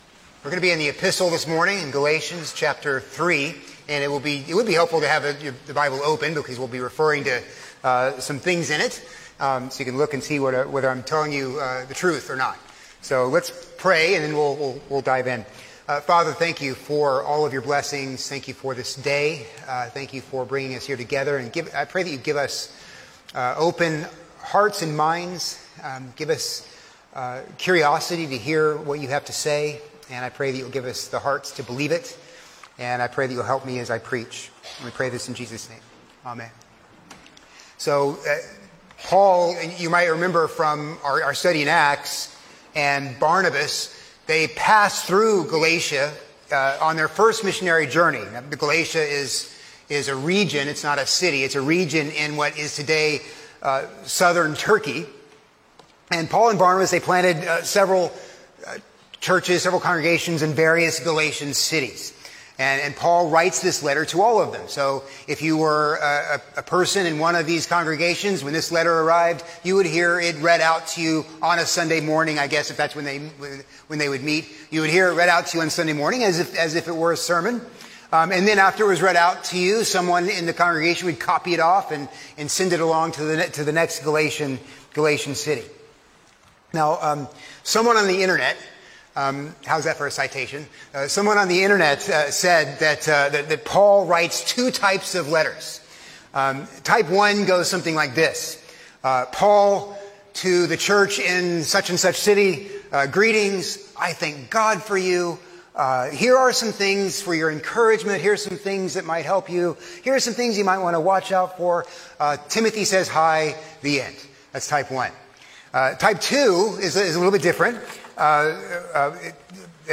A sermon on Galatians 3:23-29